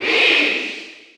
Category: Crowd cheers (SSBU) You cannot overwrite this file.
Peach_Cheer_German_SSBU.ogg